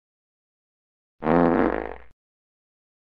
Fart